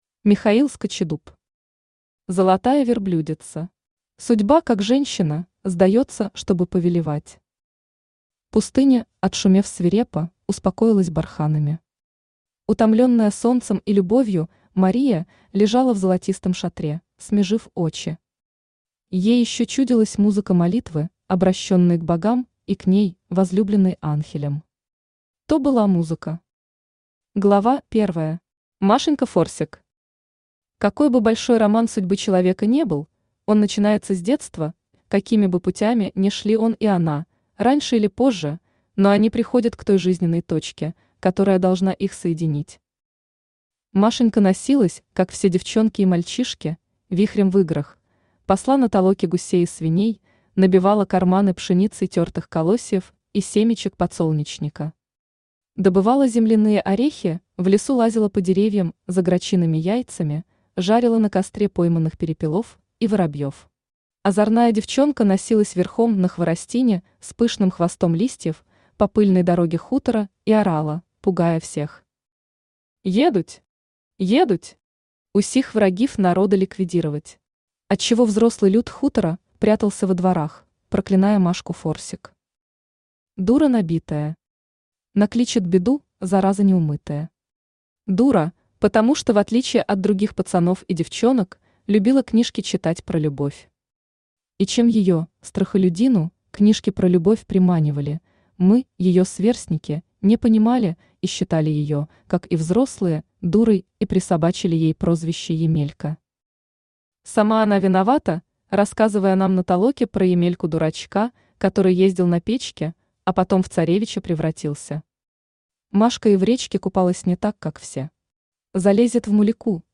Аудиокнига Золотая Верблюдица | Библиотека аудиокниг
Aудиокнига Золотая Верблюдица Автор Михаил Скачидуб Читает аудиокнигу Авточтец ЛитРес.